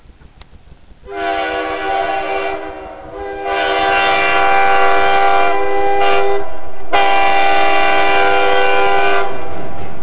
It's hard to look at the Lion's Gate Bridge and not long for the sound of a Budd car consist pulling out of the North Vancouver station on its way to Lillooet and points beyond.